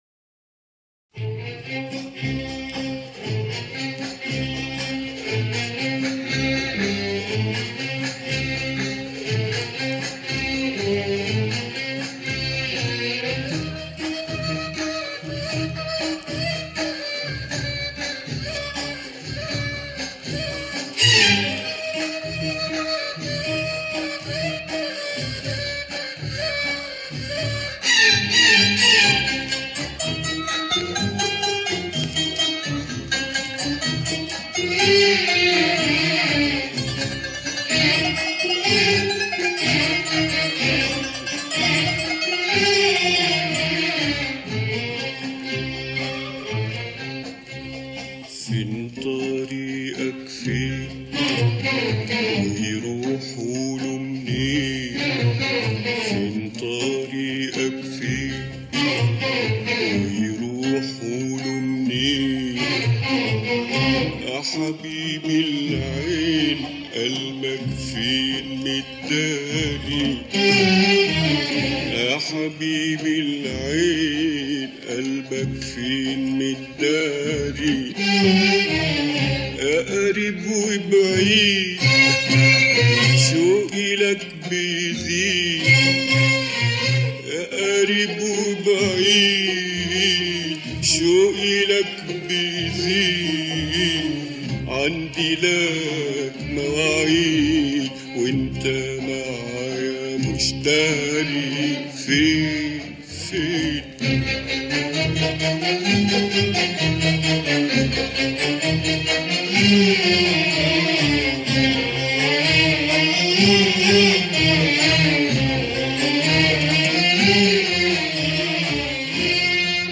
اغنية عربية